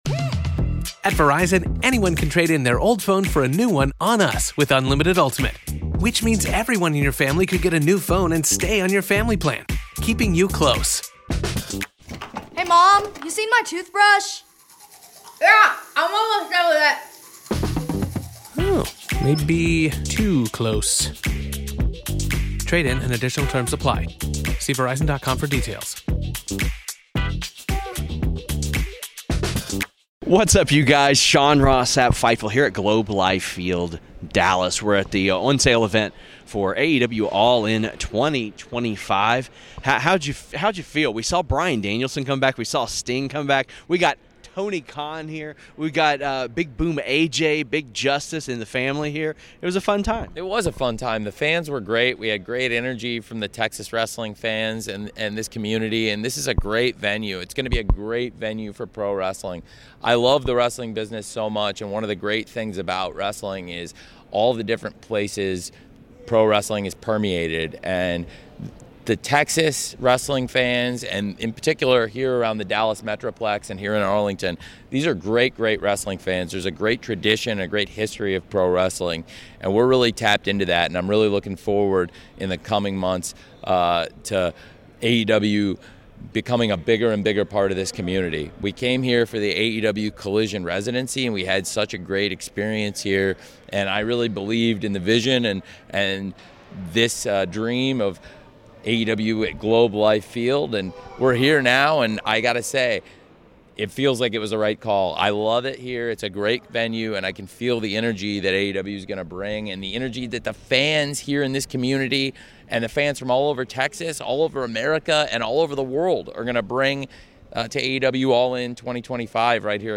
Tony Khan On AEW Live Event Strategy, New Media Deal With WBD, Simulcasting On Max | Interview | Fightful News
Shoot Interviews Dec 11